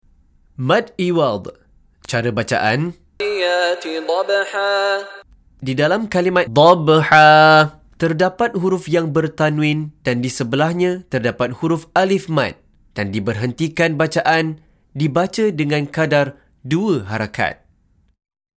Penerangan Hukum + Contoh Bacaan dari Sheikh Mishary Rashid Al-Afasy